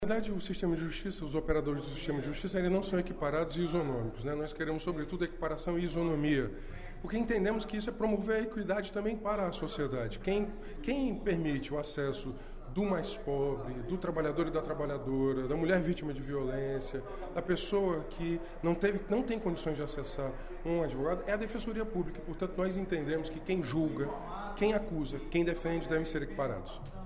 O deputado Renato Roseno (Psol) defendeu, durante o primeiro expediente da sessão plenária desta terça-feira (28/06) da Assembleia Legislativa, a equiparação e isonomia dos defensores públicos.